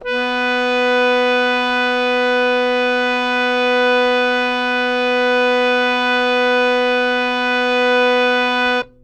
interactive-fretboard / samples / harmonium / B3.wav
B3.wav